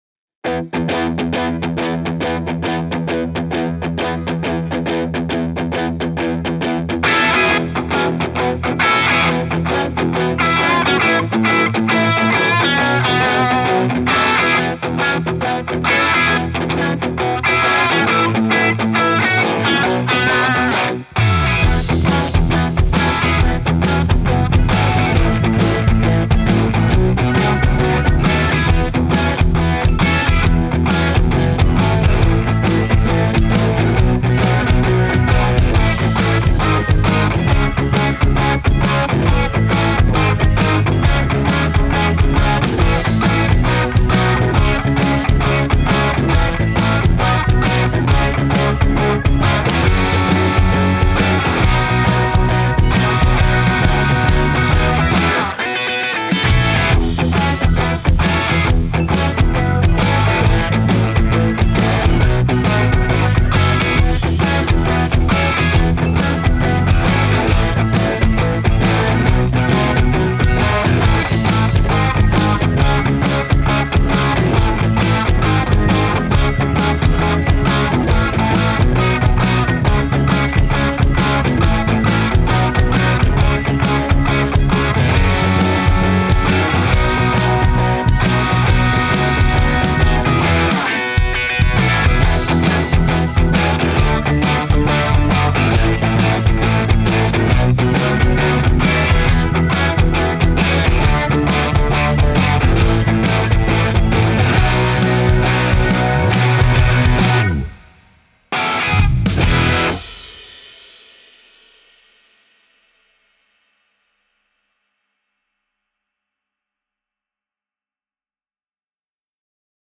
Key: E BPM: 136 Time sig: 4/4 Duration:  Size: 4.6MB
Pop Rock Worship